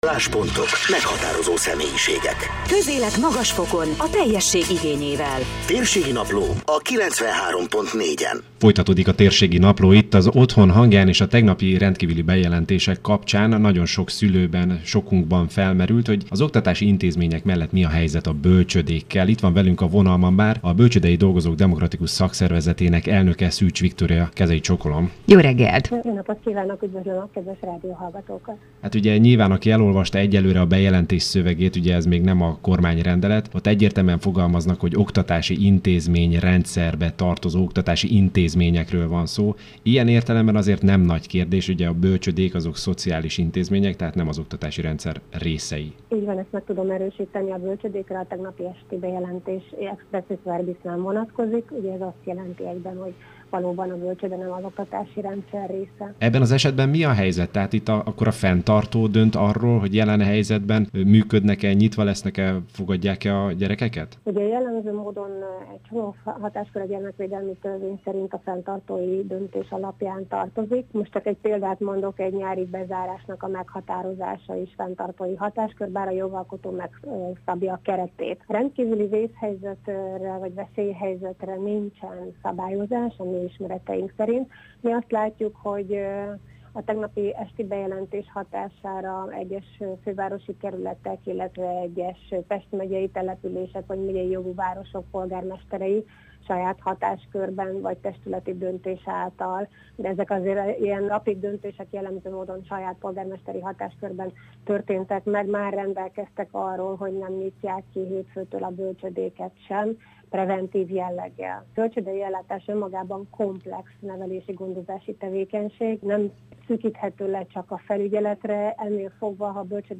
Kőszegi Zoltán március 14-i rádiós bejelentése